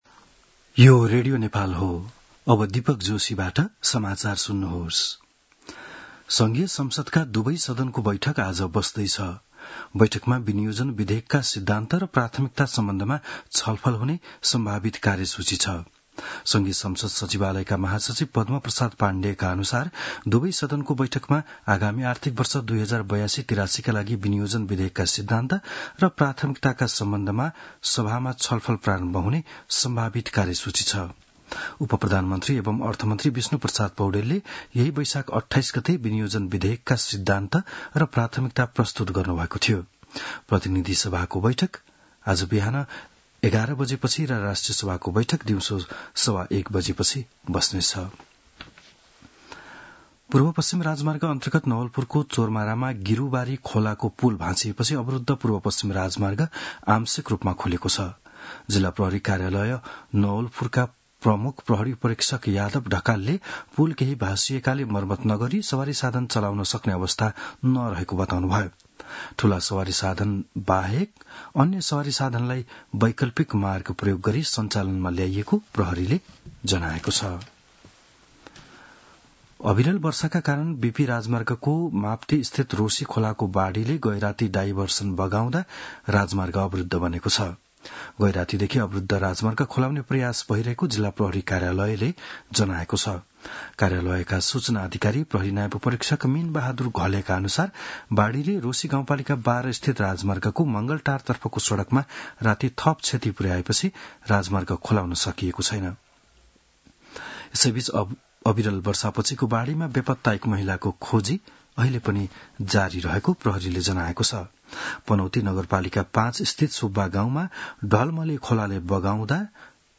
बिहान ११ बजेको नेपाली समाचार : ३० वैशाख , २०८२